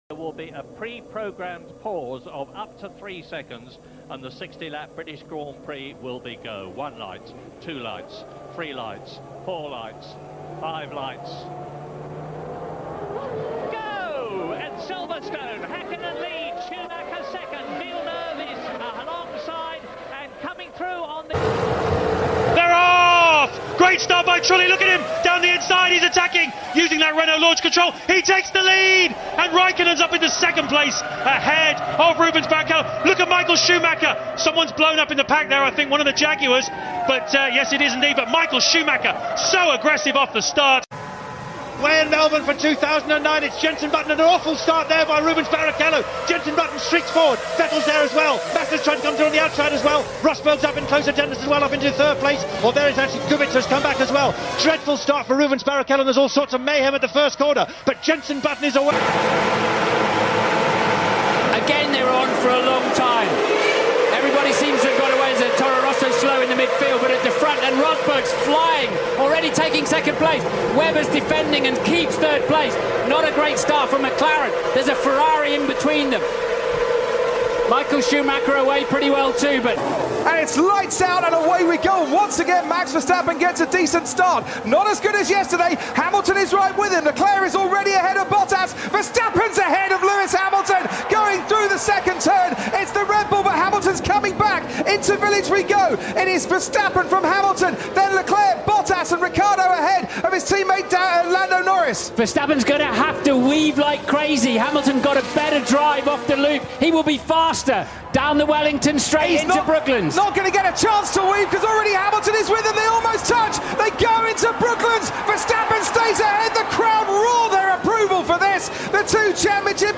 F1 Starts Through The Years Sound Effects Free Download